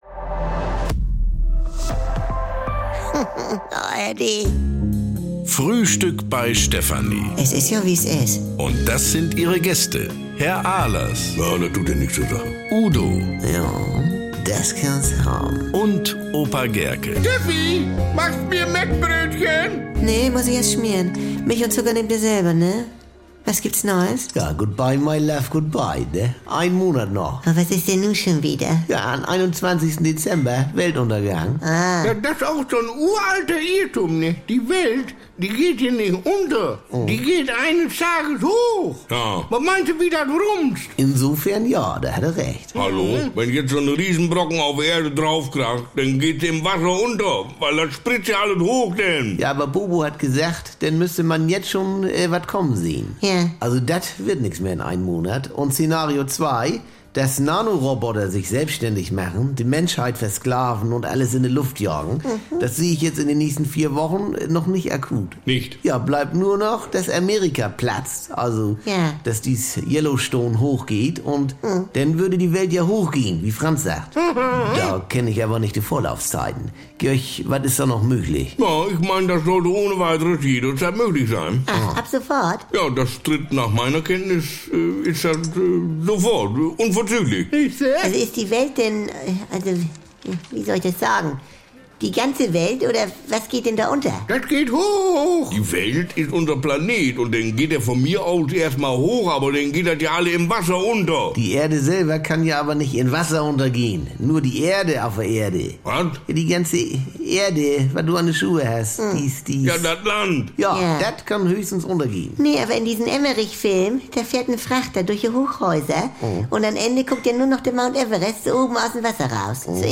besten Folgen der Kult-Comedy gibt es im Radio bei den NDR